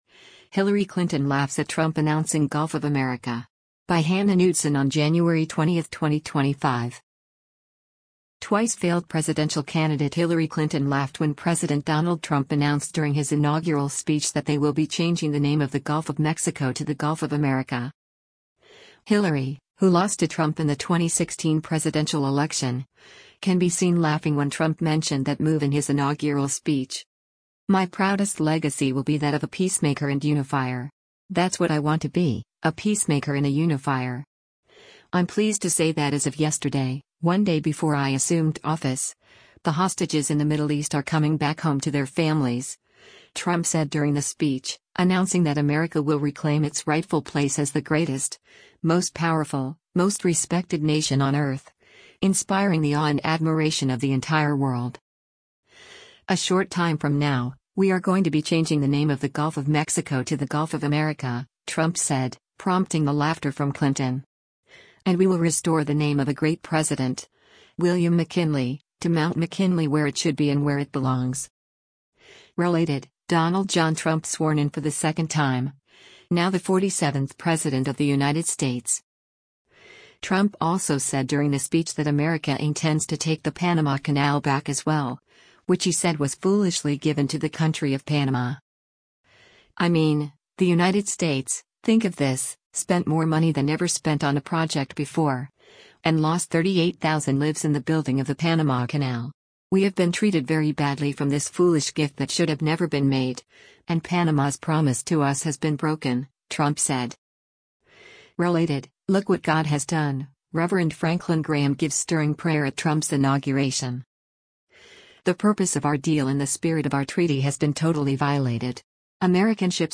Twice-failed presidential candidate Hillary Clinton laughed when President Donald Trump announced during his inaugural speech that they will be changing the name of the Gulf of Mexico to the Gulf of America.